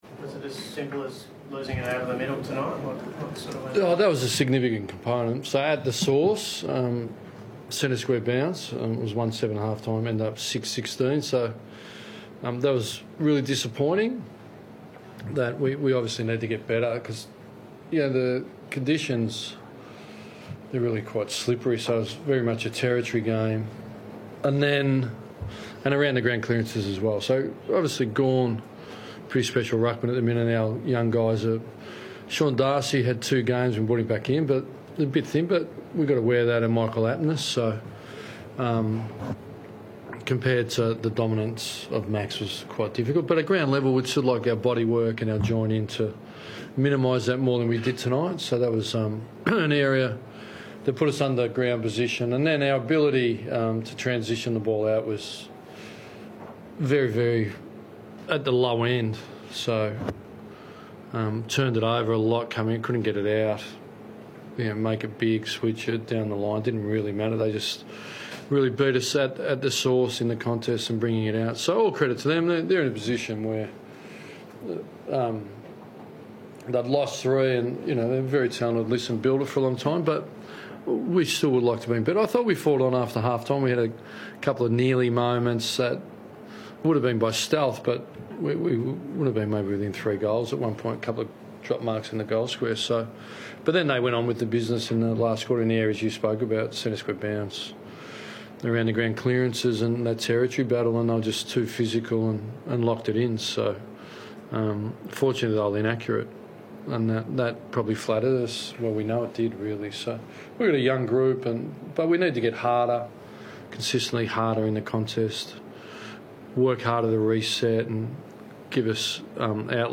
Ross Lyon spoke to media following the Round 16 clash against the Demons.